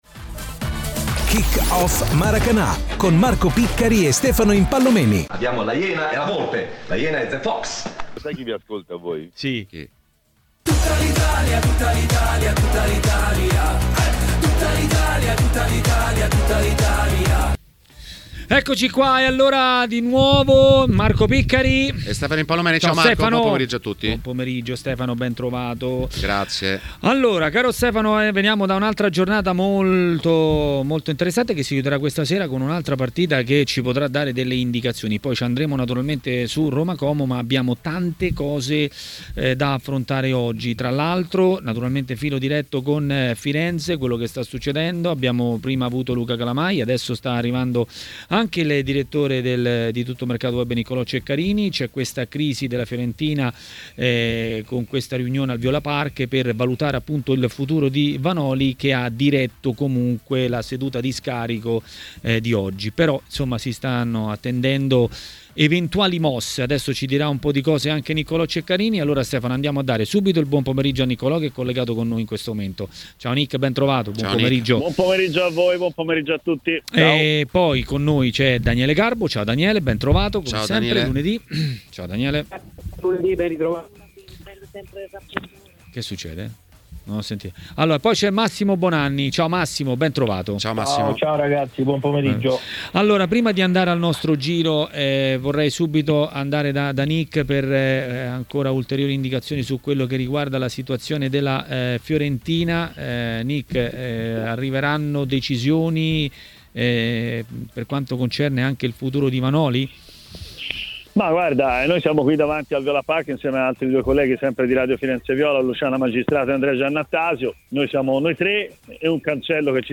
ha parlato dei temi del giorno a TMW Radio, durante Maracanà.